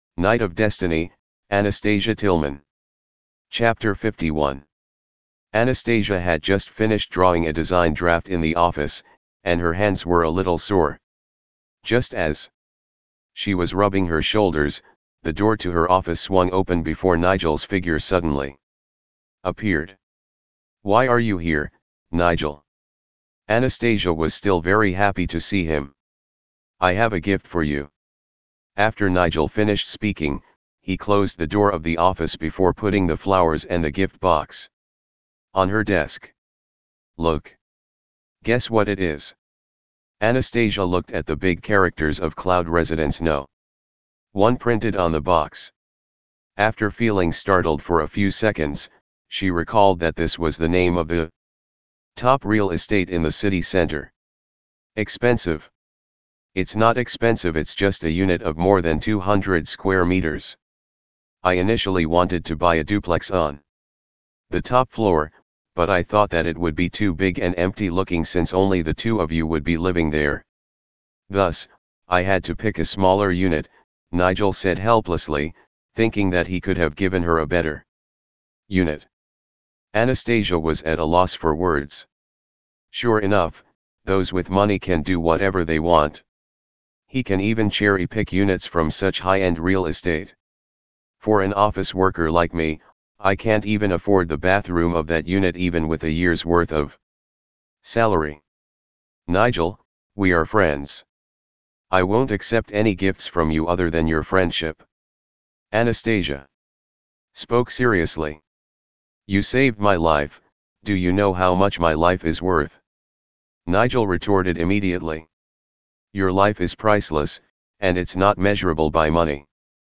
Night of Destiny Audiobook and PDF version Chapter 51 to 60